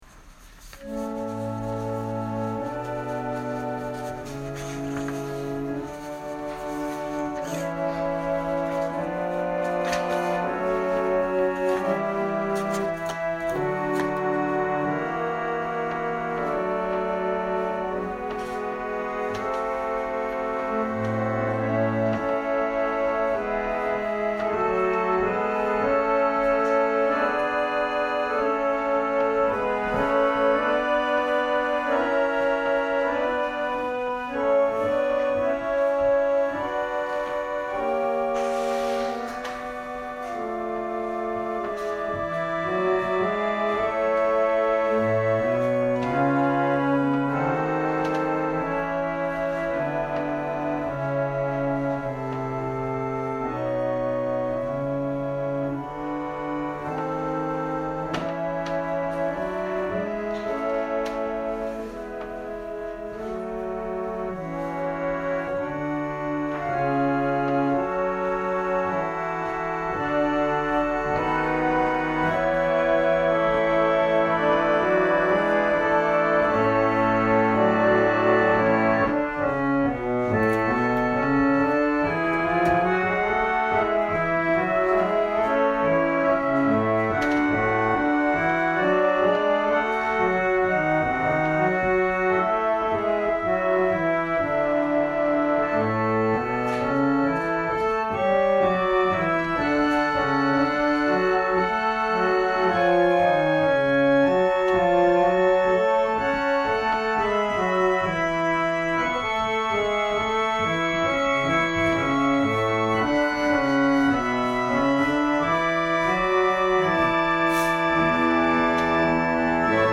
2021年09月26日朝の礼拝「神の嗣業の地 하나님께서 주시는 기업」せんげん台教会
説教アーカイブ。